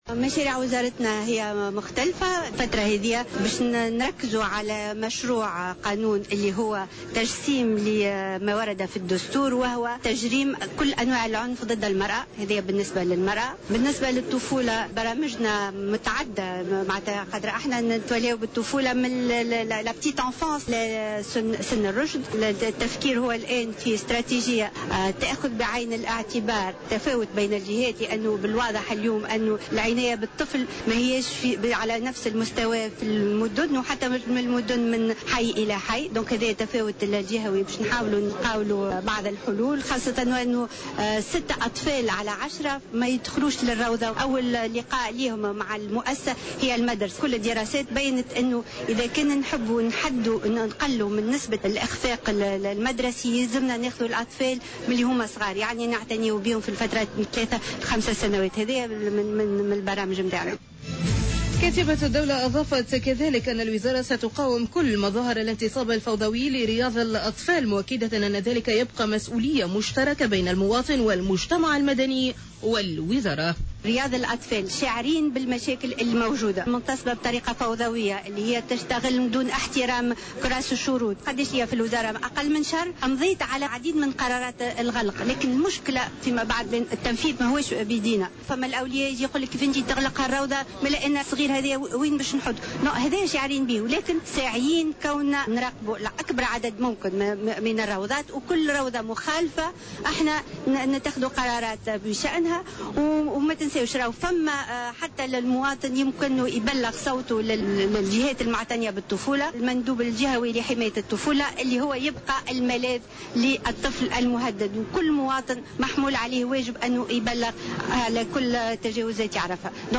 La secrétaire d'Etat pour la femme et la famille, Neila Chaabane a déclaré au micro de notre correspondant à Sfax que le programme du ministère sera axé principalement sur l'application des dispositions de la Constitution, notamment, la criminalisation contre toute forme de violence à l'encontre des femmes.